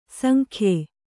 ♪ sankhye